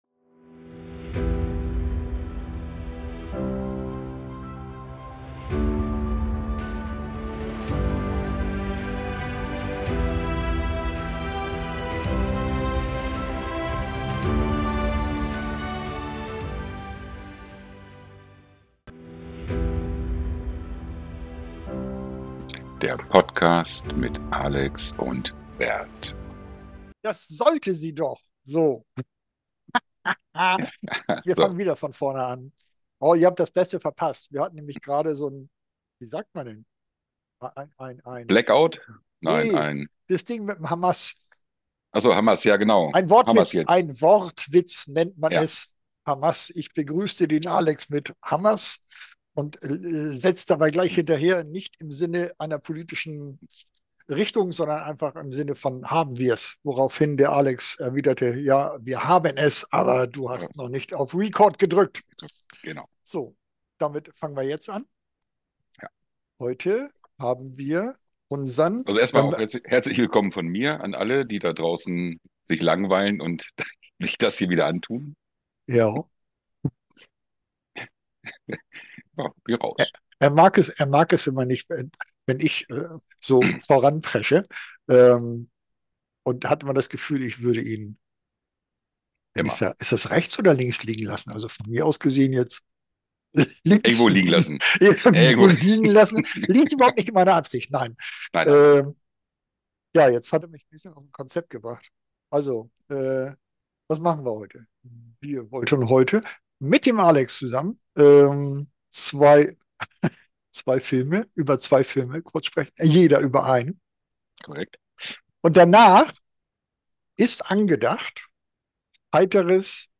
Leider mussten wir die Musiksequenzen (aus urheberrechtlichen Gründen) rausschneiden...